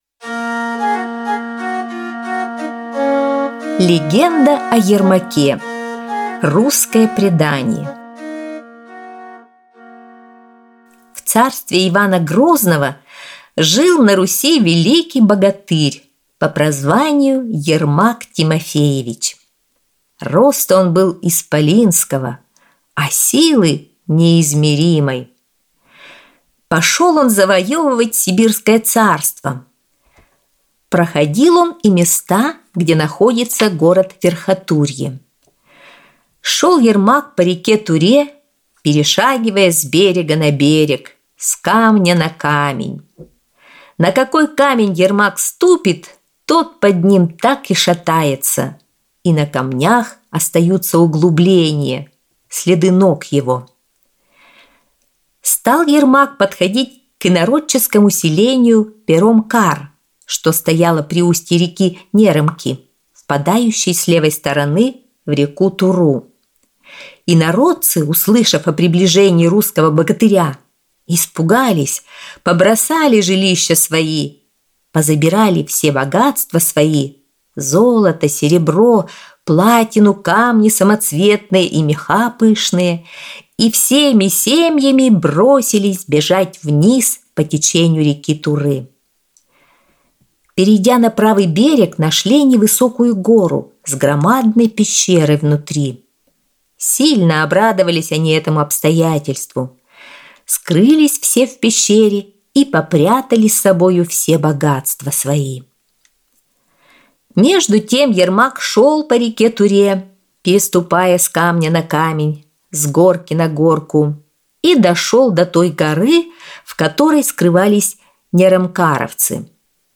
Аудиосказка «Легенда о Ермаке»